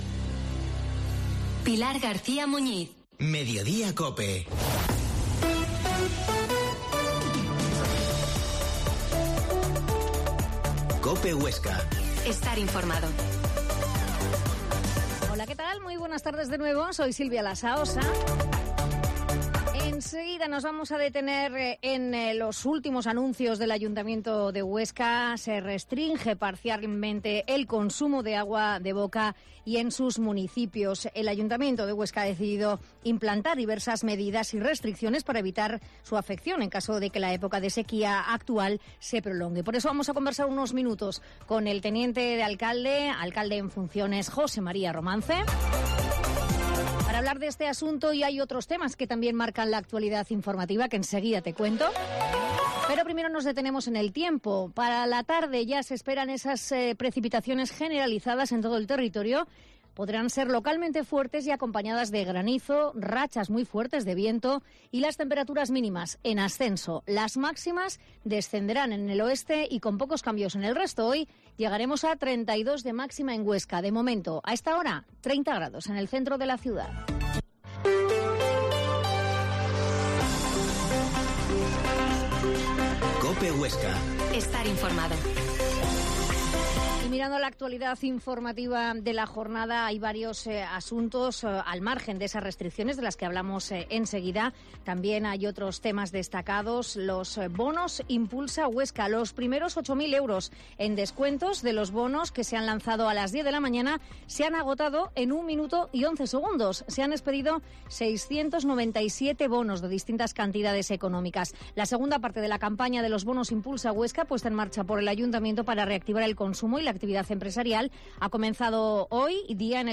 Mediodia en COPE Huesca 13.50h Entrevista al alcalde en funciones José Mª Romance